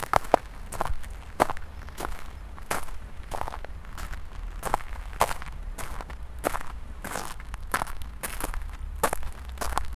Footsteps on gravel
footsteps-on-gravel-dt56ba5f.wav